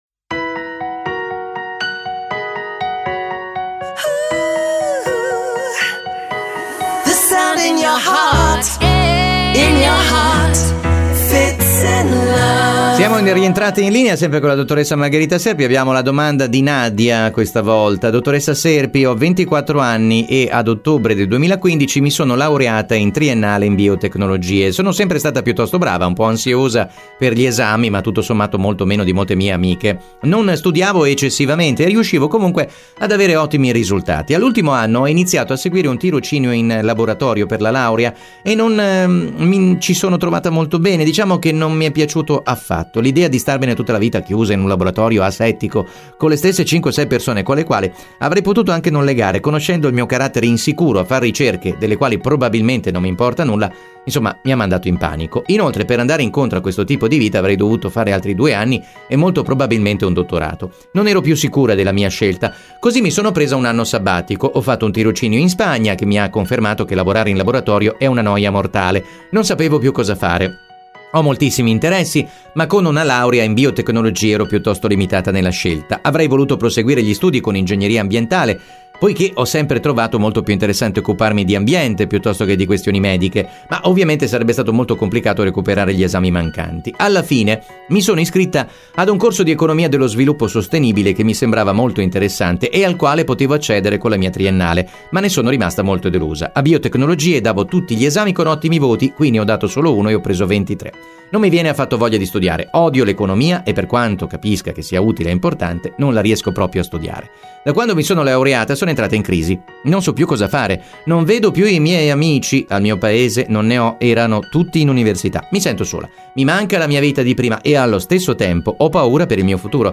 psicologa e psicoterapeuta.